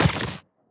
biter-roar-mid-2.ogg